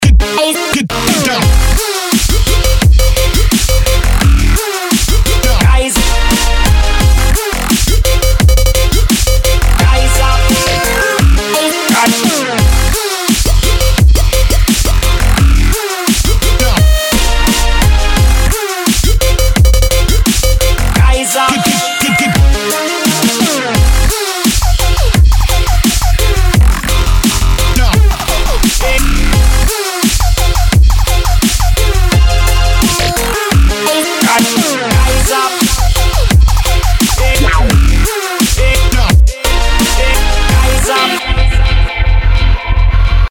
• Качество: 192, Stereo
Драмстеп-новинка